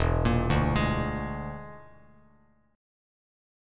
E7b5 chord